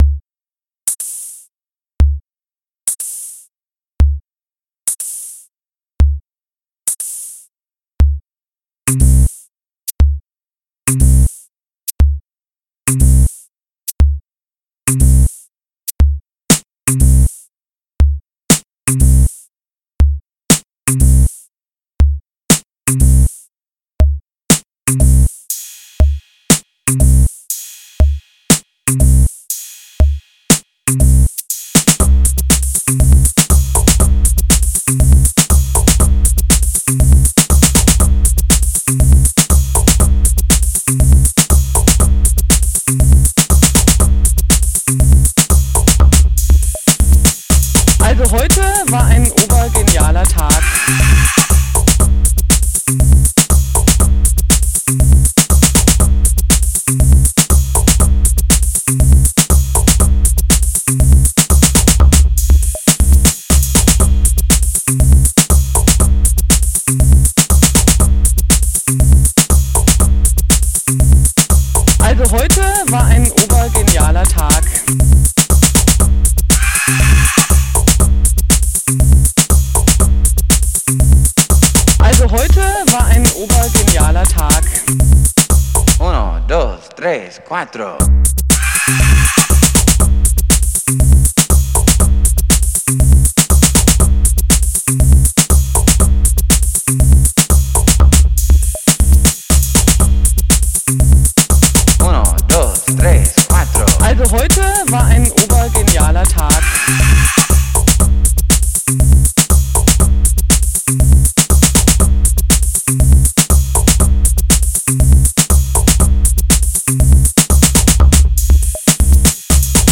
Sieben akustische Schnappschüsse.
Tanzbar.